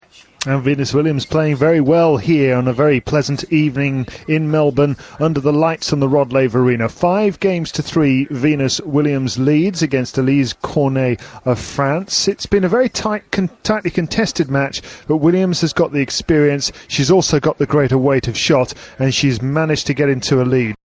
【英音模仿秀】满血状态约战莎娃 听力文件下载—在线英语听力室